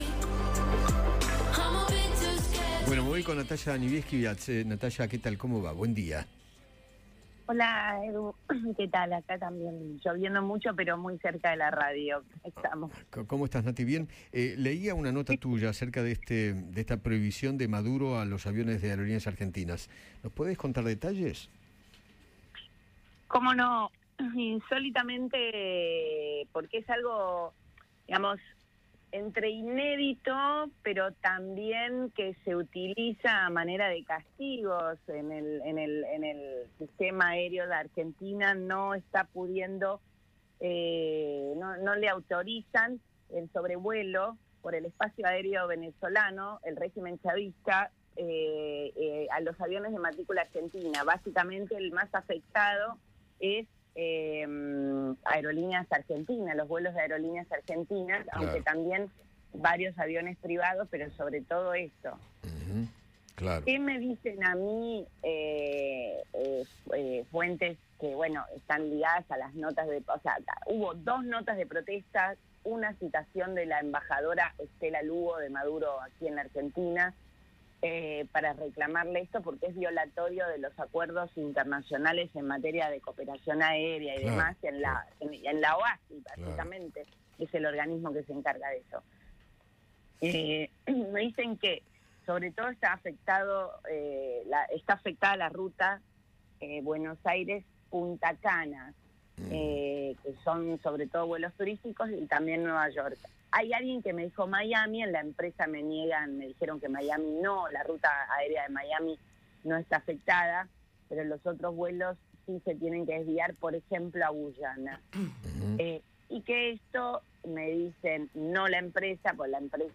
conversó con la periodista